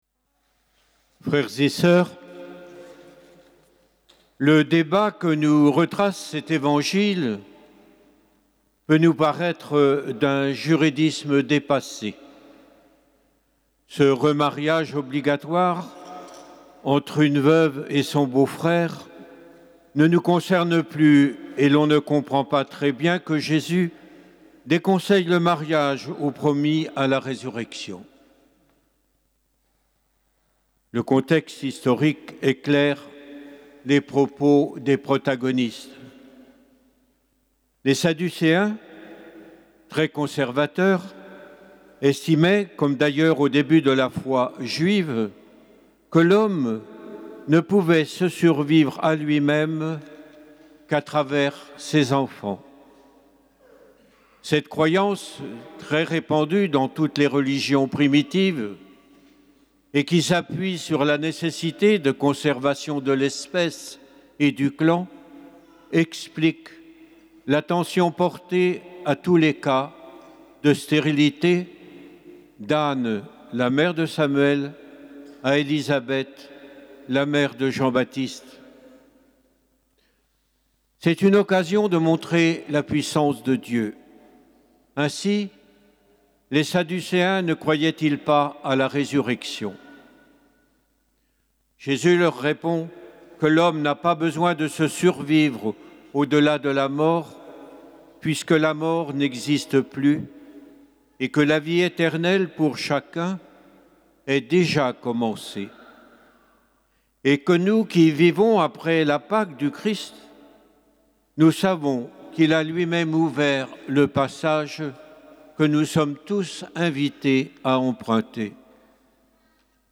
Homélie du Bluteau